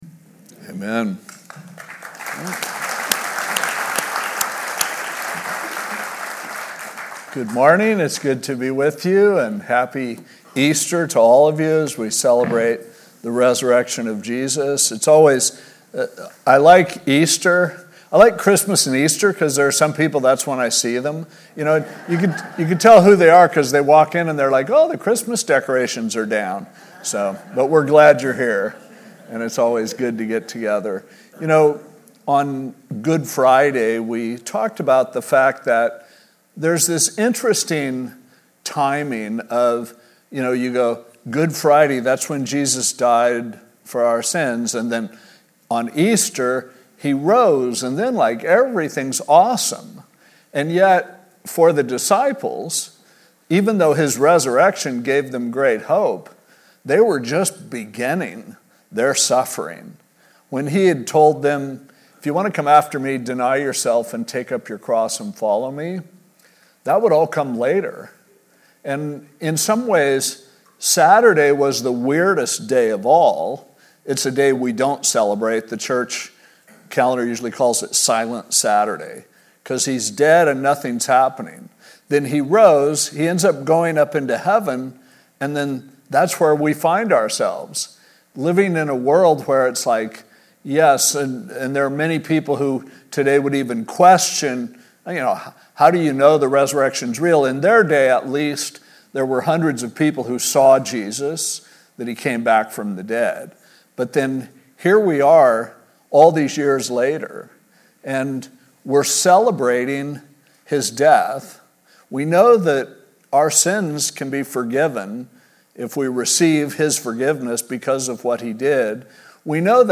From Series: "Holiday Messages"